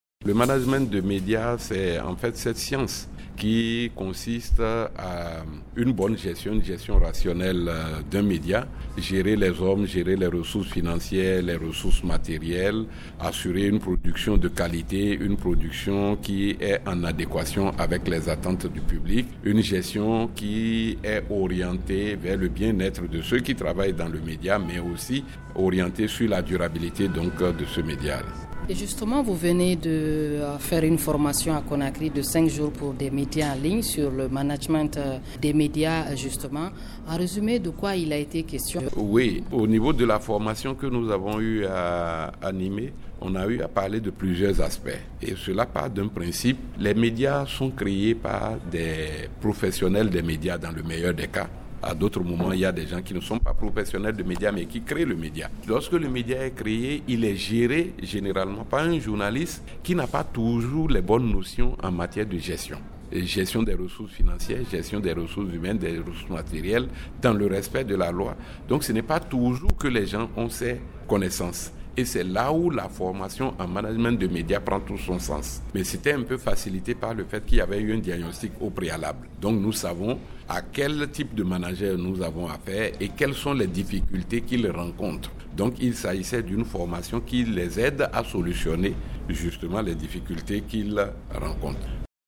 au micro radio guinée